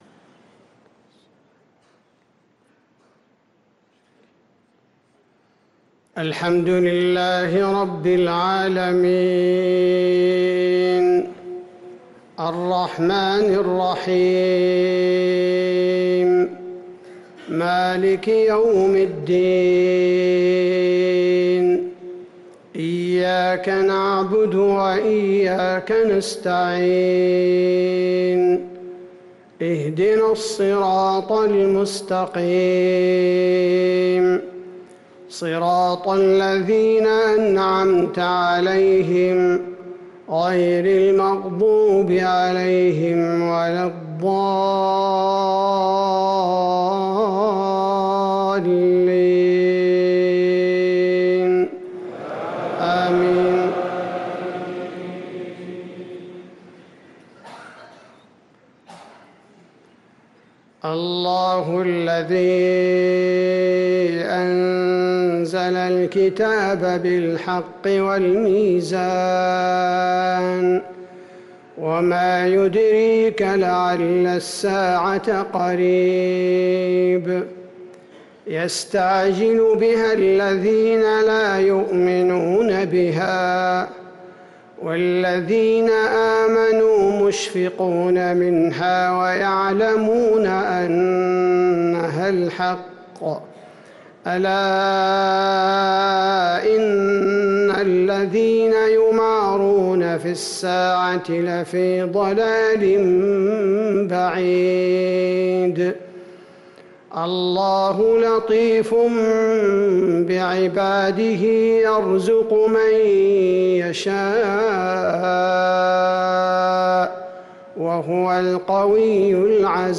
صلاة المغرب للقارئ عبدالباري الثبيتي 4 ربيع الأول 1445 هـ
تِلَاوَات الْحَرَمَيْن .